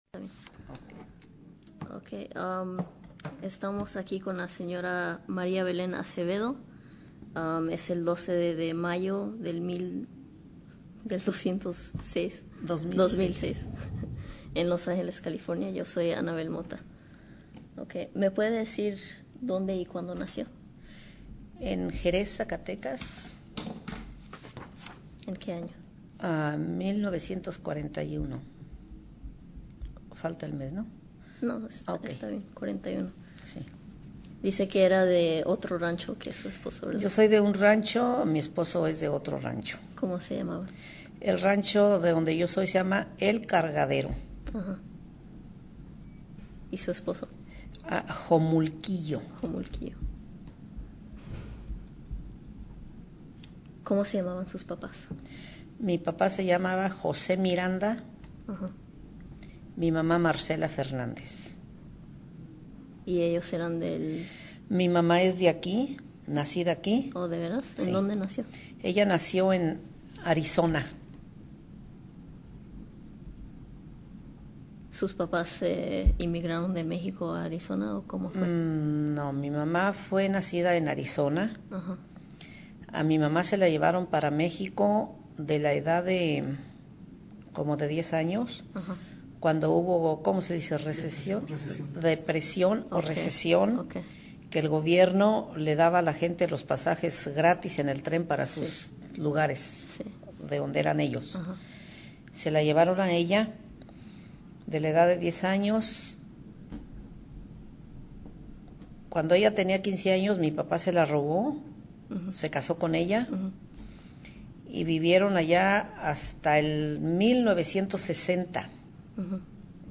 Summary of Interview: